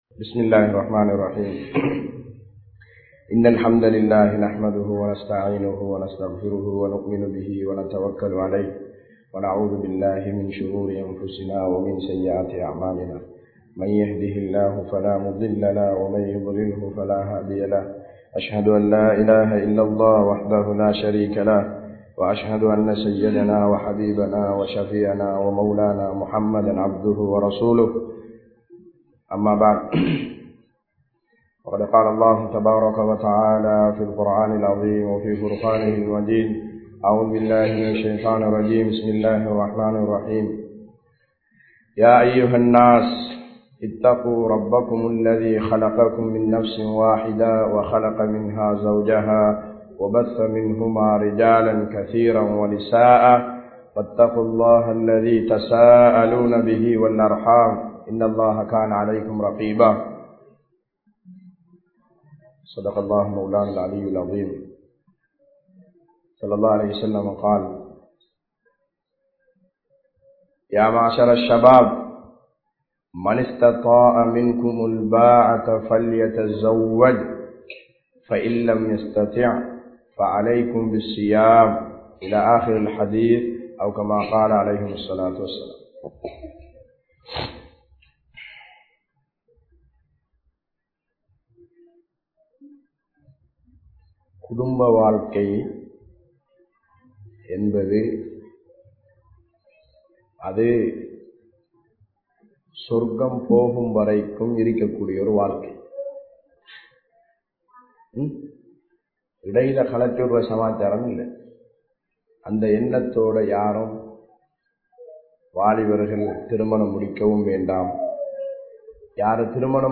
Suvarkaththai Noakkiya Kudumba Vaalkai (சுவர்க்கத்தை நோக்கிய குடும்ப வாழ்க்கை) | Audio Bayans | All Ceylon Muslim Youth Community | Addalaichenai
Addalaichenai 07, Masjithur Ravaha